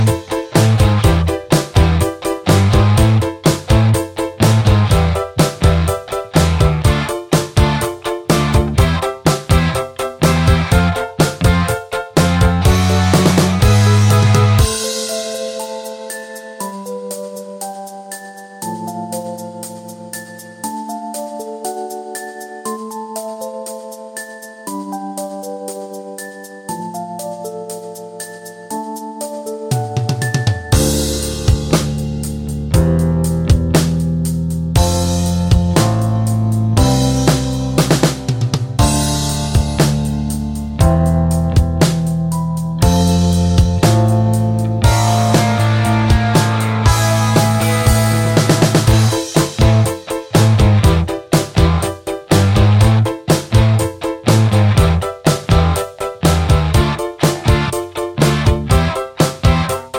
no Backing Vocals Glam Rock 3:51 Buy £1.50